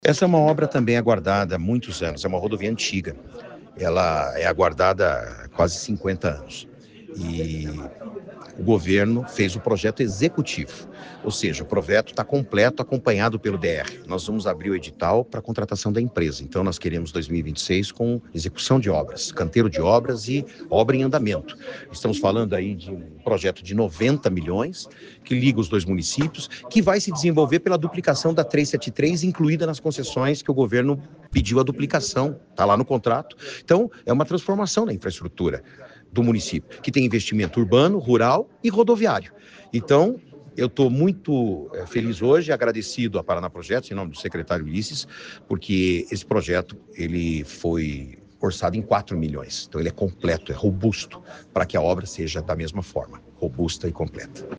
Sonora do secretário de Infraestrutura e Logística, Sandro Alex, sobre a pavimentação da PR-487 entre Ivaí e Ipiranga